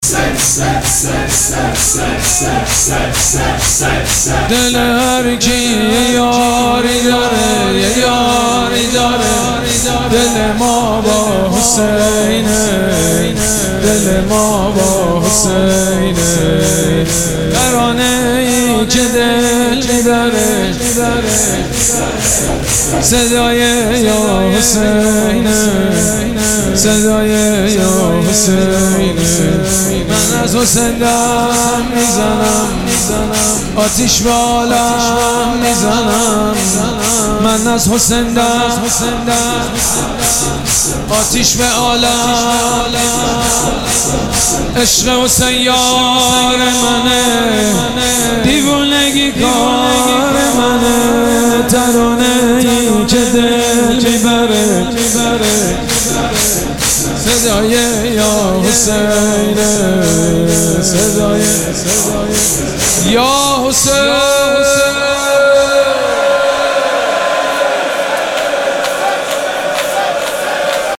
مراسم عزاداری شب دهم محرم الحرام ۱۴۴۷
شور
مداح
حاج سید مجید بنی فاطمه